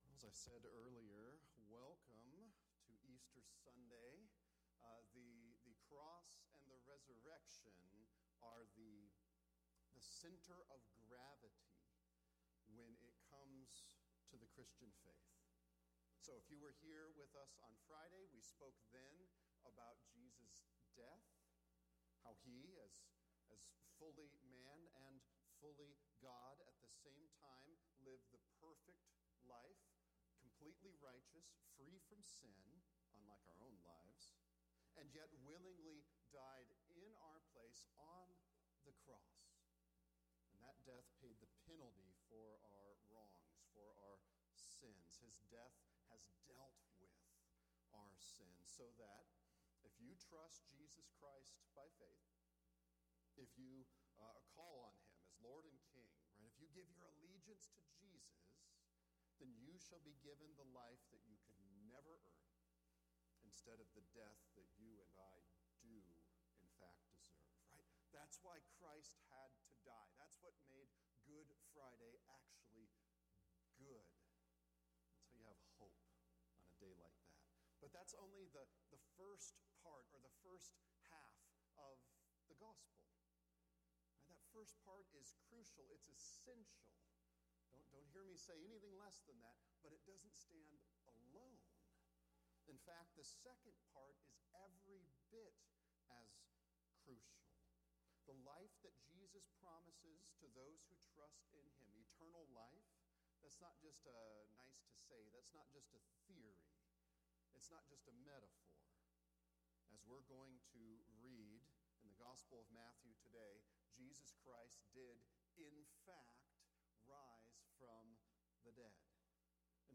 Matthew 28:1-10 Resurrection Joy – Sermons